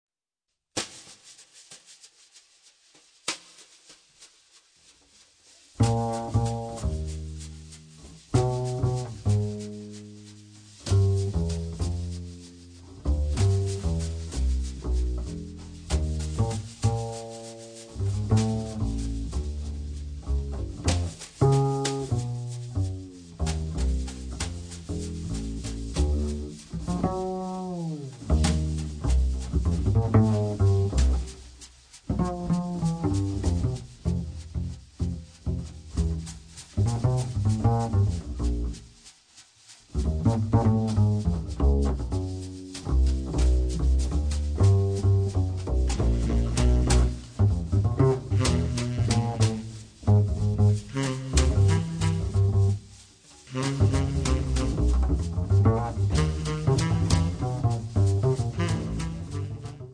sax tenore e soprano, live electronics
chitarra
contrabbasso
batteria e percussioni